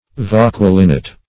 Search Result for " vauquelinite" : The Collaborative International Dictionary of English v.0.48: Vauquelinite \Vauque"lin*ite\, n. [So called after the French chemist Vauquelin, who died in 1829: cf. F. vauquelinite.]